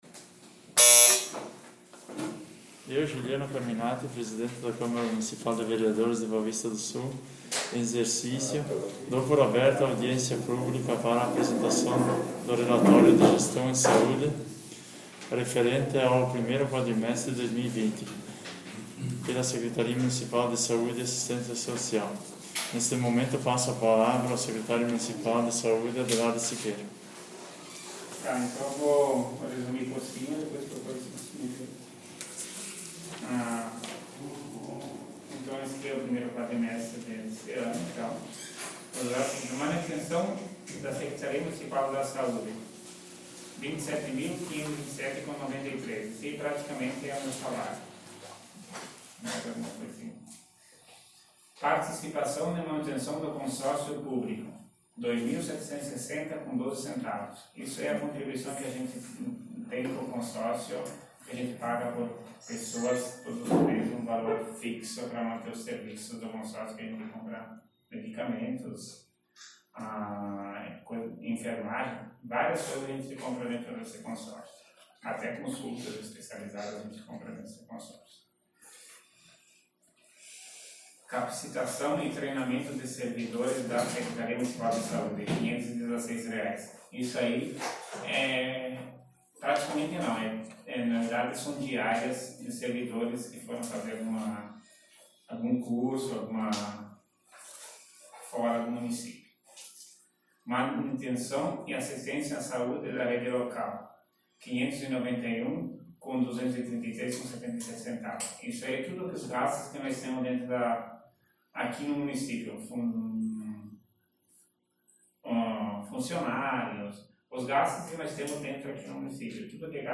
Áudio da Audiência Pública 1º Qd-2020 — Câmara Municipal de Boa Vista do Sul